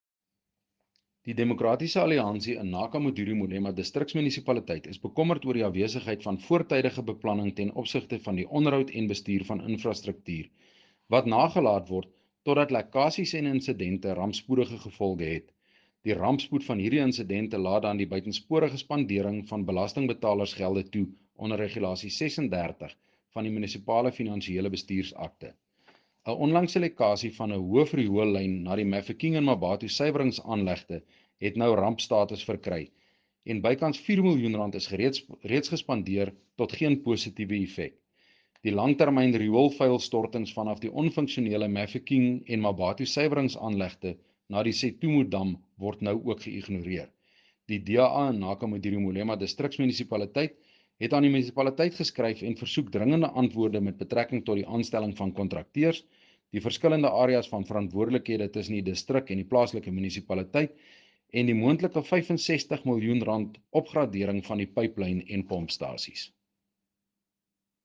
Note to Broadcasters: Please find linked soundbites in
Rdl-Cornel-Dreyer-Riool-Afr.mp3